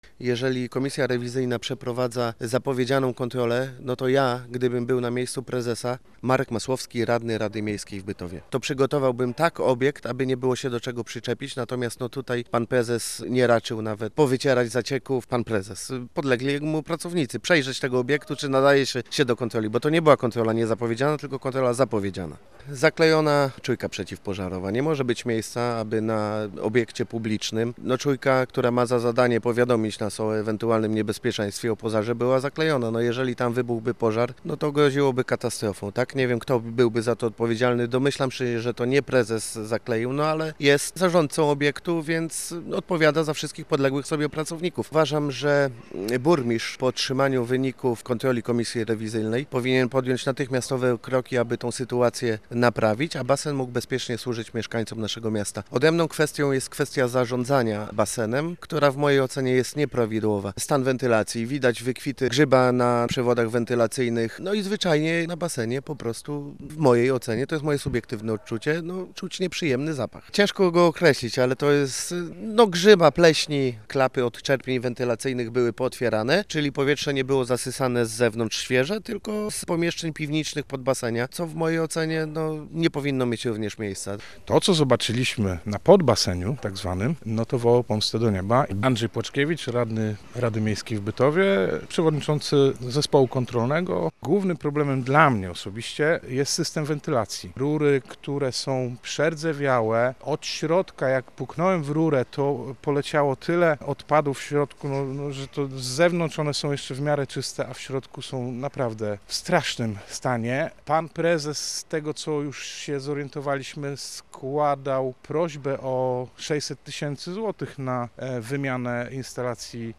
Posłuchaj materiału naszej reporterki: https